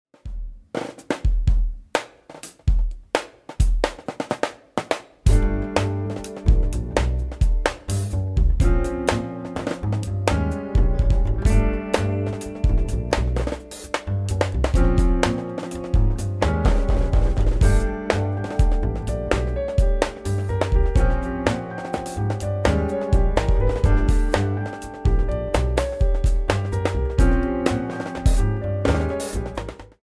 trumpet
Music recorded 2011 at Loft, Cologne